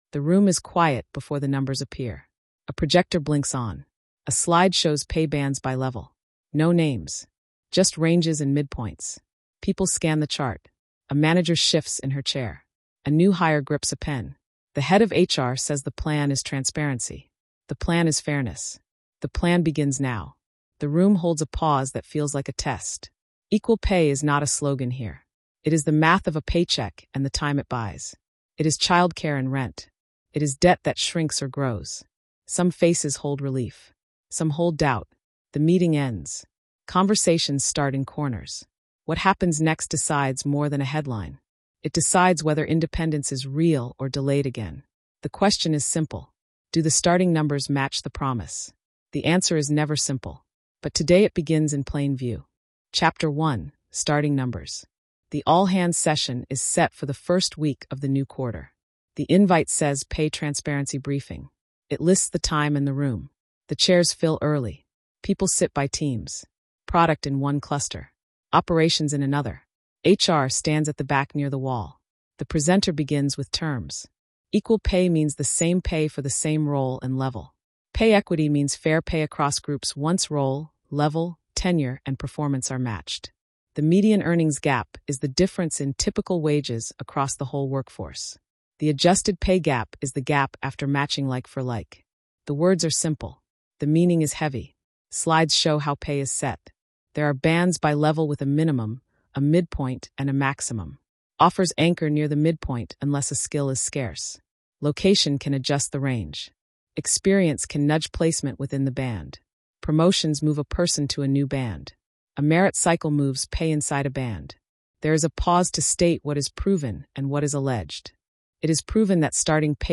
The reporting stays exact and humane: clean definitions, proven versus alleged claims, and on-the-ground voices from workers, stewards, analysts, and managers.